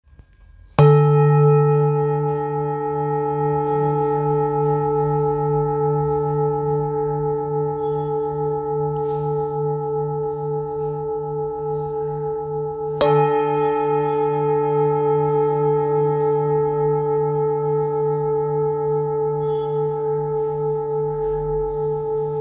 Singing Bowl, Buddhist Hand Beaten, with Fine Etching Carving of Yellow Jambhala, Thangka Color Painted, Select Accessories
Material Seven Bronze Metal